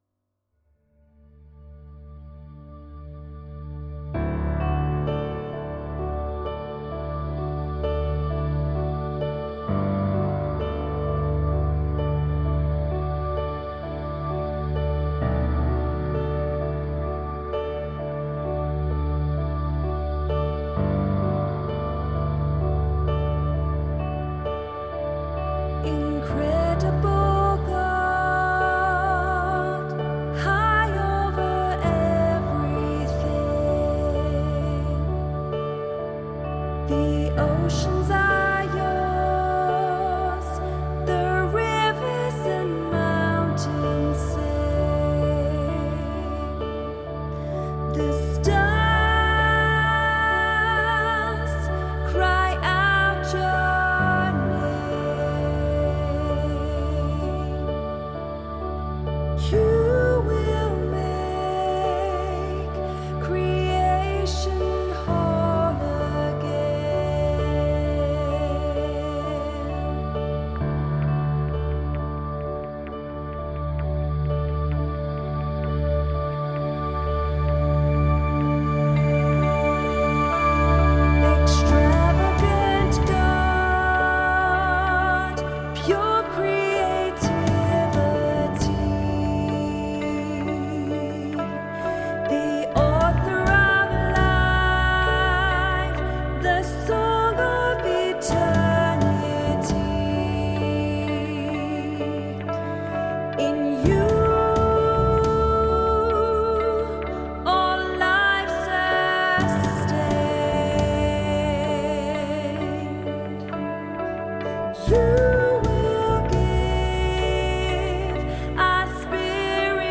• Atmospheric
• Worship